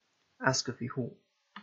Ayscoughfee Hall /ˈæskəˌf hɔːl/
En-UK-Ayscoughfee_Hall.ogg.mp3